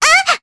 Luna-Vox_Damage_jp_02.wav